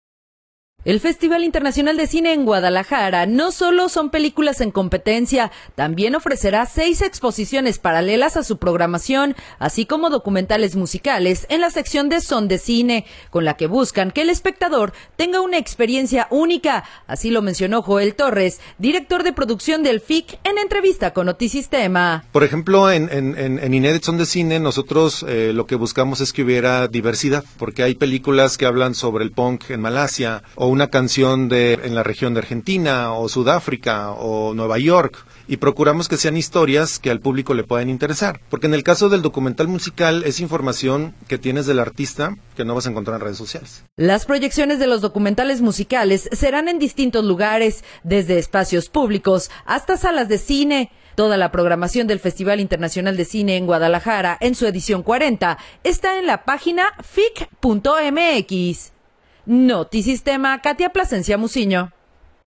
en entrevista con Notisistema